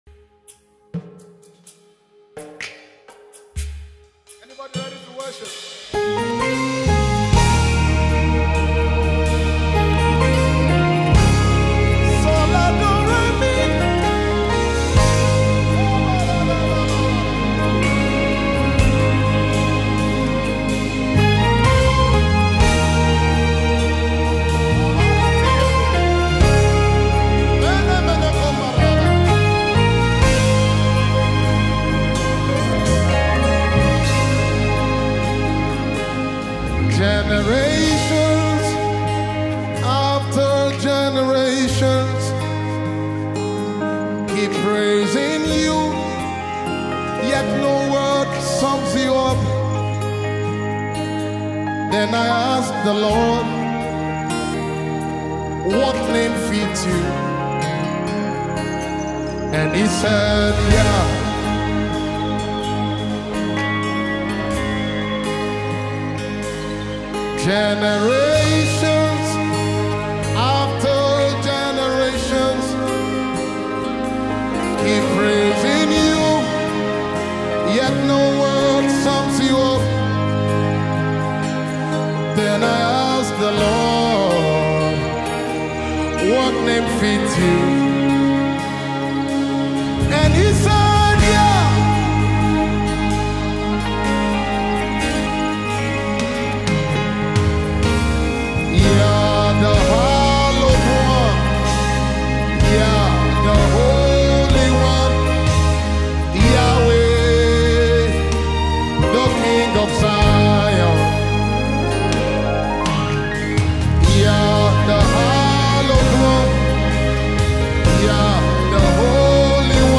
Nigerian Gospel musician